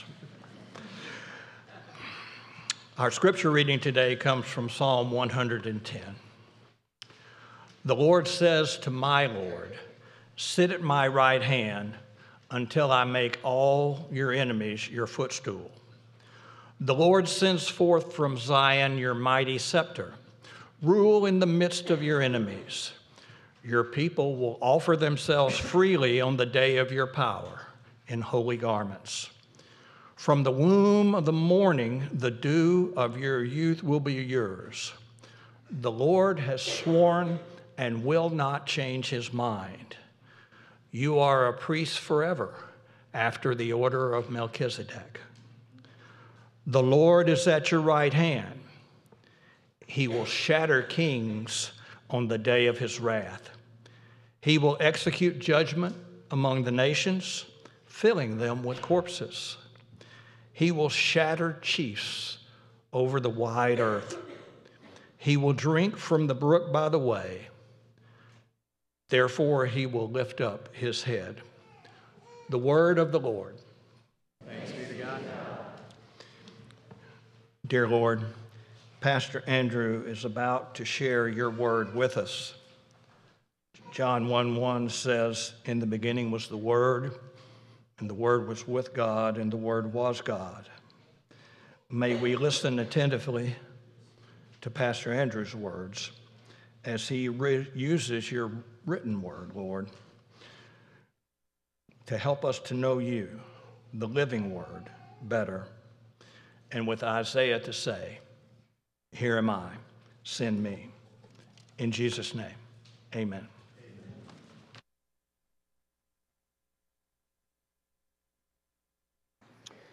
8.17.25 sermon.m4a